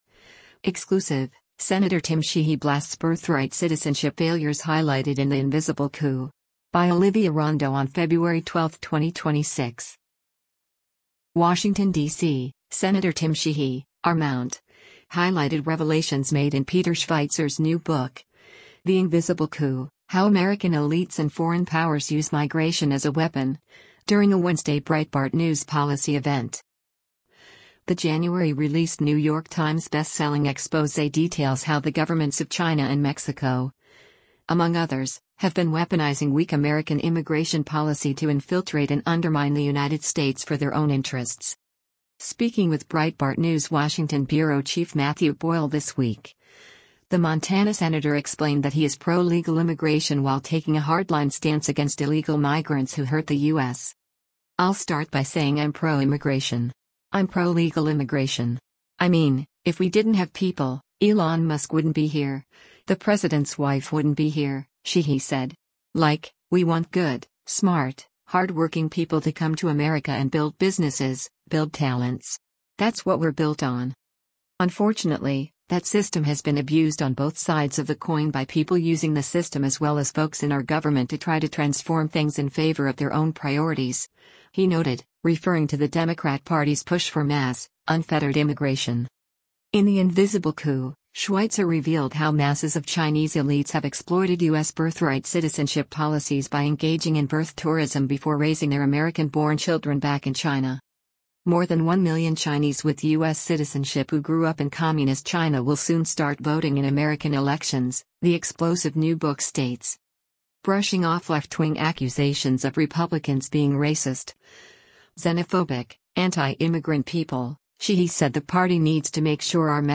Washington, DC — Sen. Tim Sheehy (R-MT) highlighted revelations made in Peter Schweizer’s new book, The Invisible Coup: How American Elites and Foreign Powers Use Migration as a Weapon, during a Wednesday Breitbart News policy event.